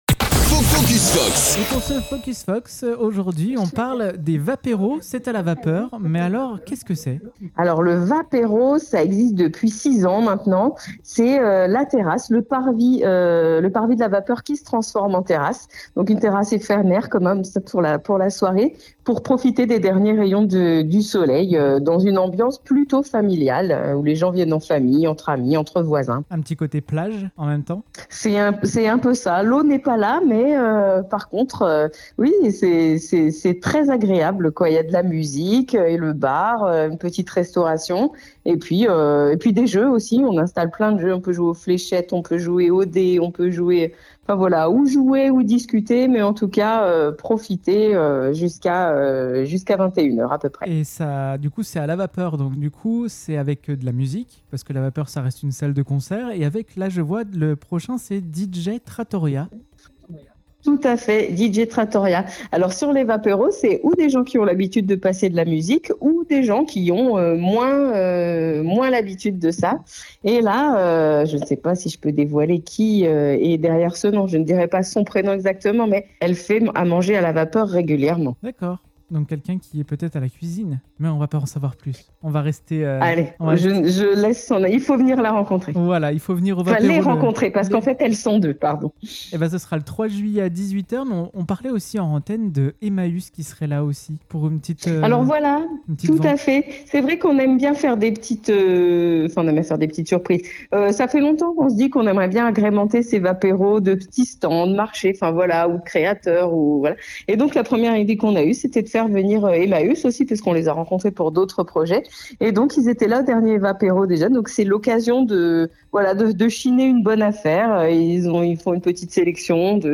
Par téléphone